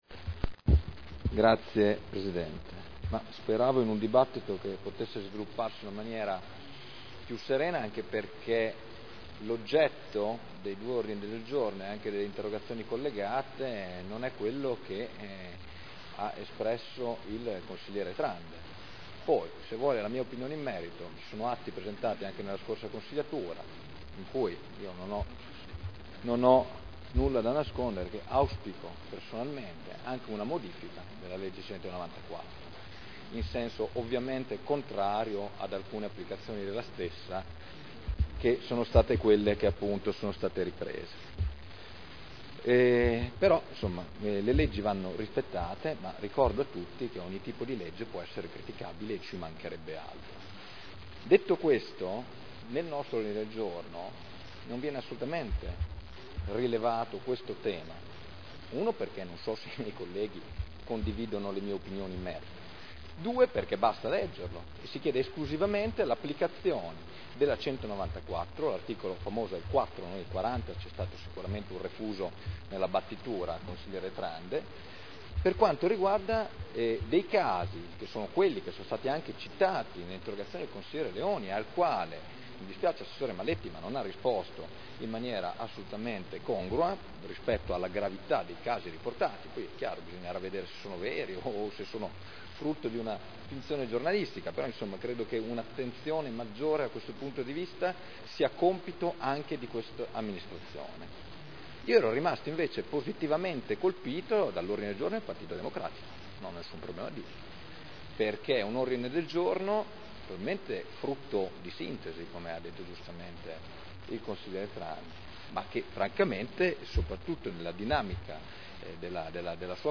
Seduta del 01/02/2010.